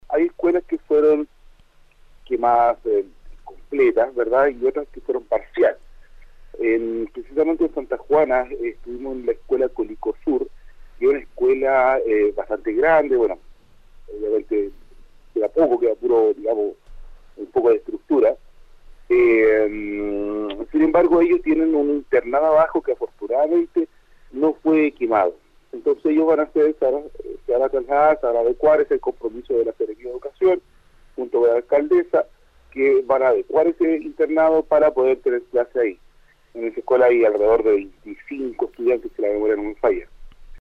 en entrevista con Radio UdeC.